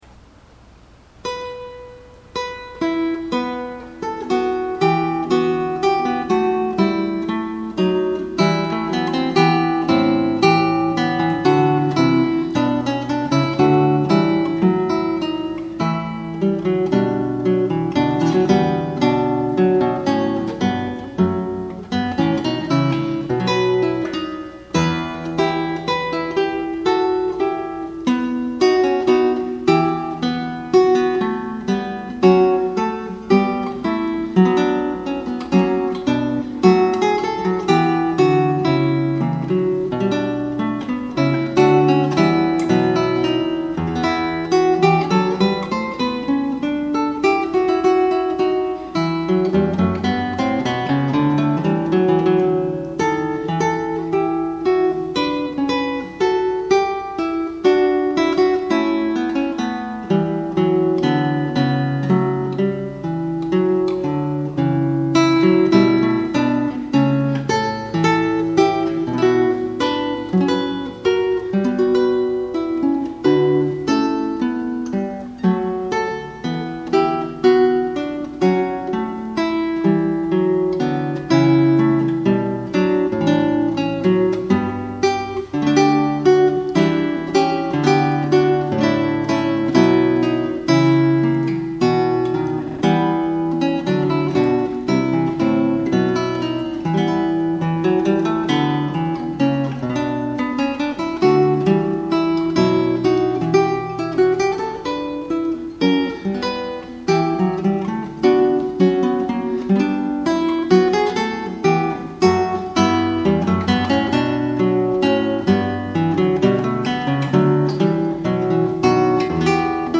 Fuenllana Fantasia 7 - performed on flamenco guitar